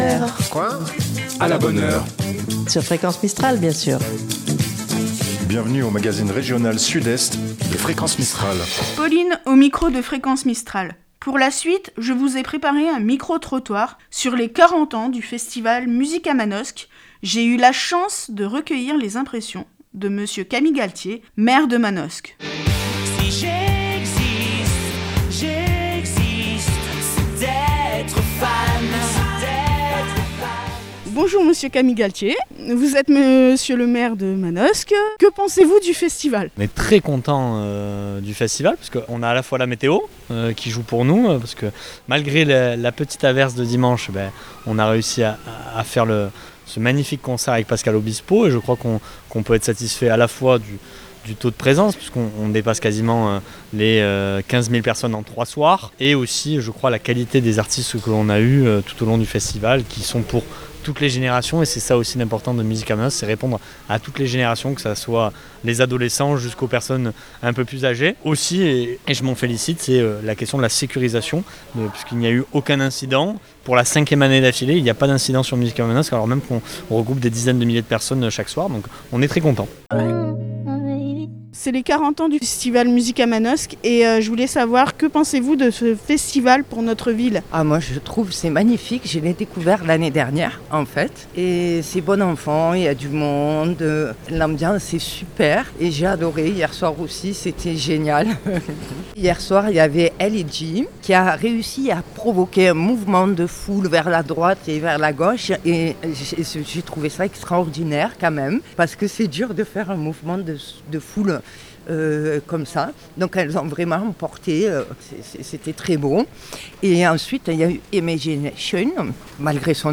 Micro-Trottoir